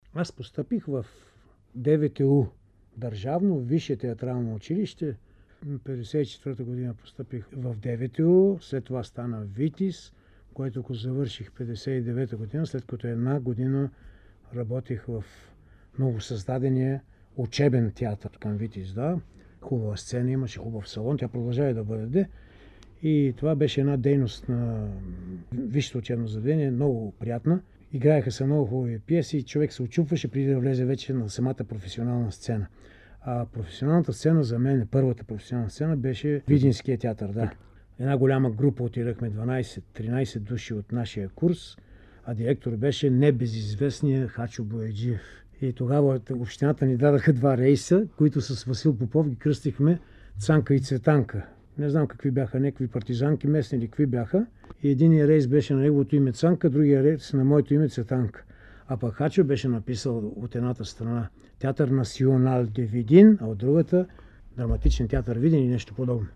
Актьорът разказва за началото на театралната си кариера в запис от 1997 година, Златен фонд на БНР: